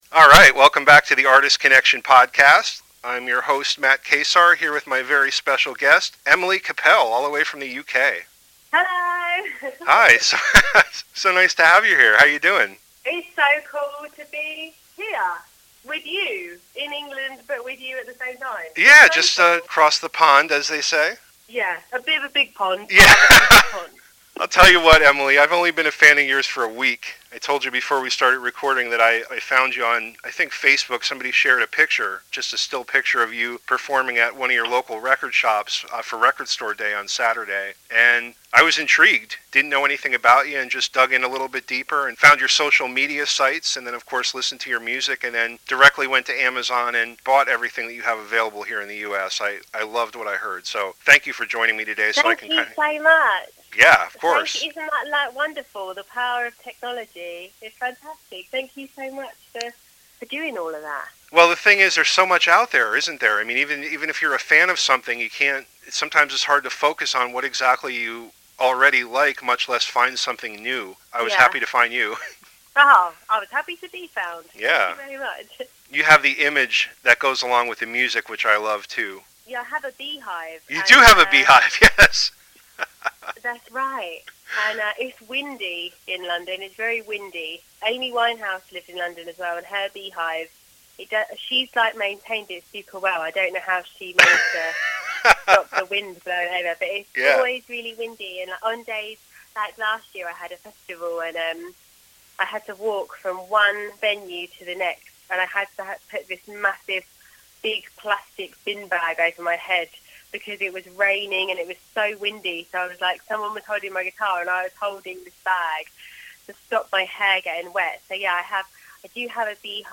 This episode mixes music and my conversation with this dynamic performer. Her songs are catchy but also make you think.